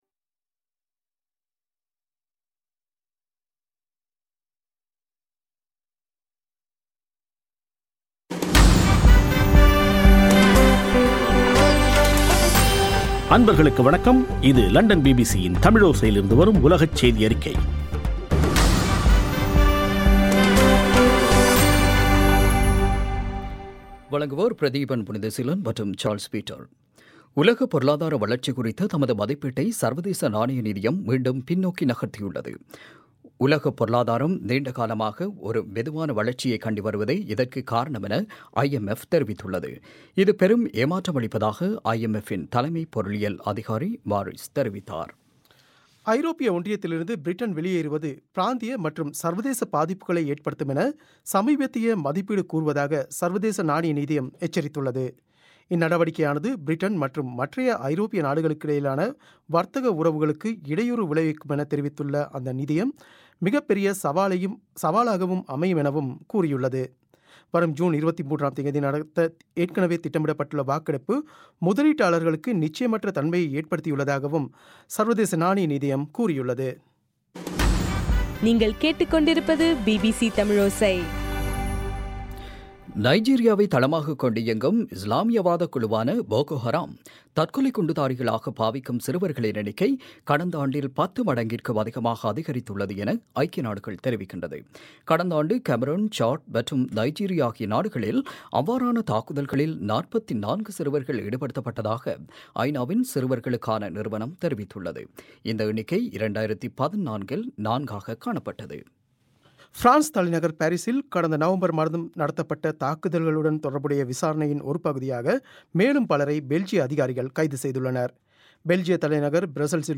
இன்றைய (ஏப்ரல் 12) பிபிசி தமிழோசை செய்தியறிக்கை